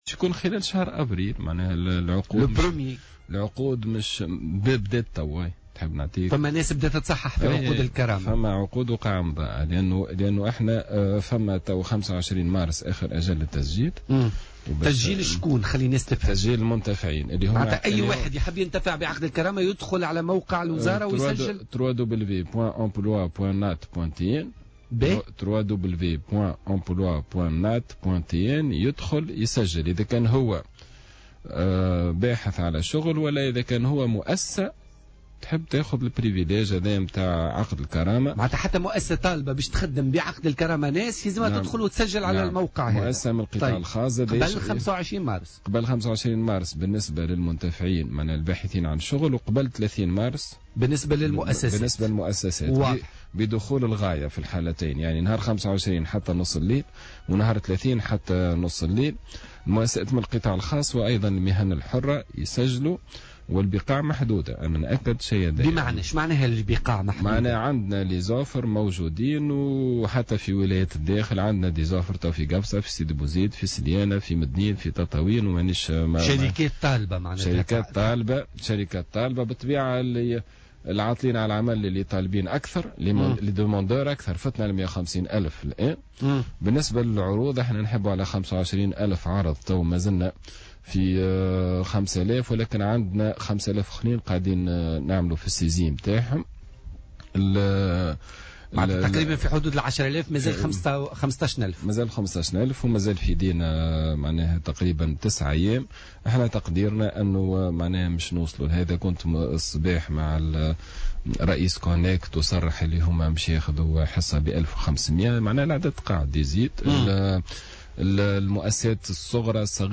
وأضاف في مداخلة له اليوم في برنامج "بوليتيكا" أنه تم تحديد يوم 25 مارس 2017 كآخر أجل لتسجيل المنتفعين من طالبي الشغل وقبل يوم 30 مارس بالنسبة للمؤسسات الخاصة الراغبة في الانتداب.